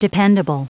Prononciation du mot dependable en anglais (fichier audio)
Prononciation du mot : dependable